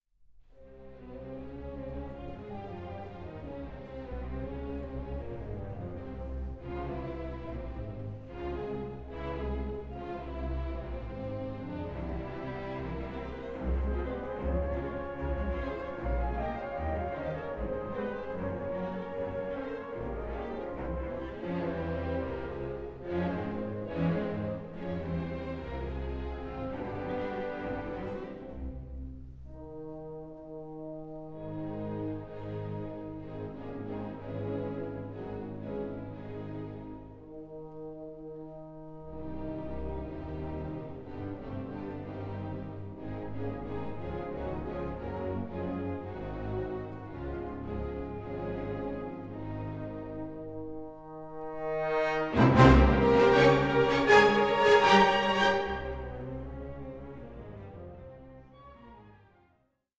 Allegro 8:54